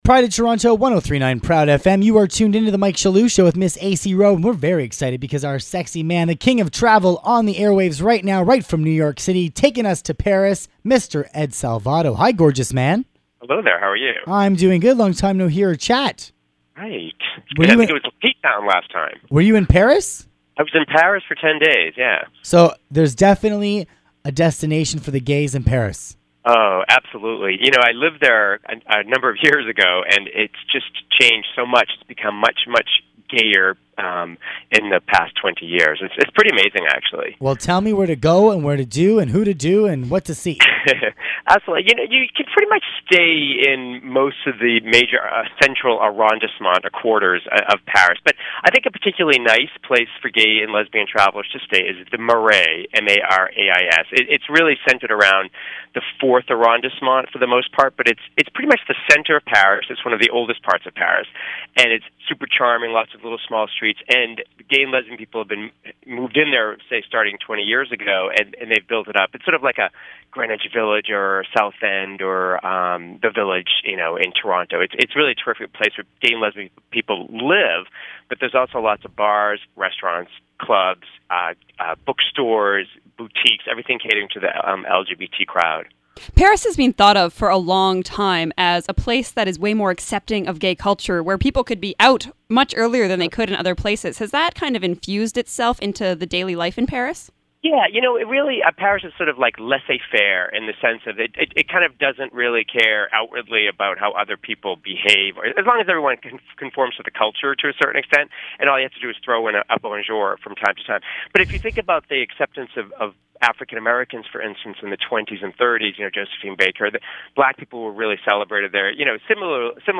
I recently spoke about Pairs on Proud FM radio .